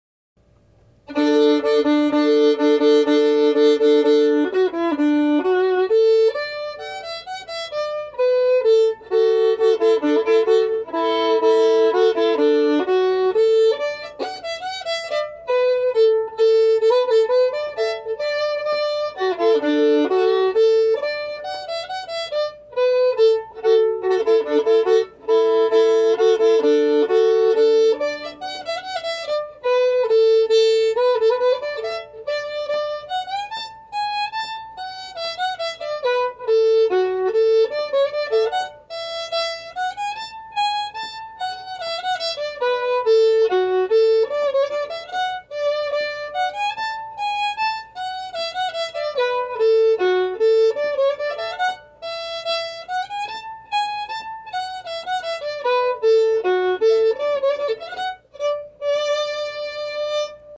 Key: D
Form: Reel
Played slowly for learning
Source: Trad.
Genre/Style: Old-time